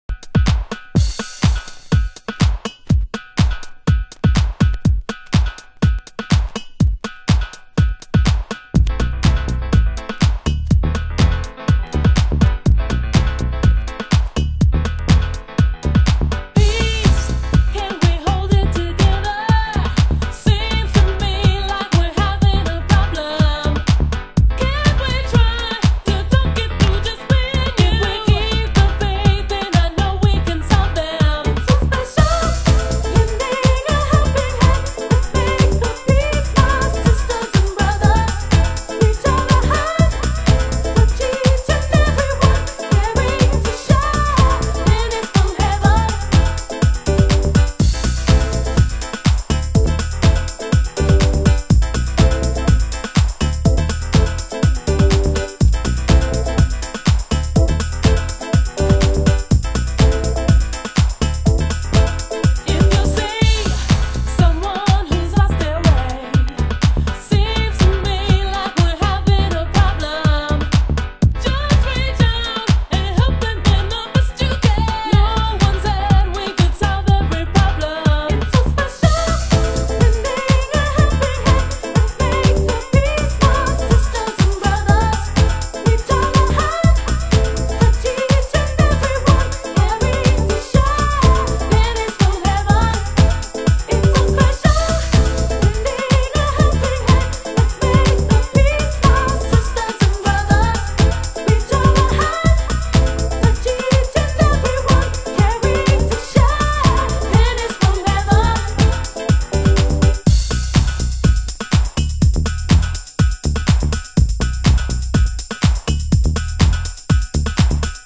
盤質：盤面良好ですが、イントロ部にチリパチノイズ有　　ジャケ：良好